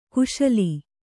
♪ kuśali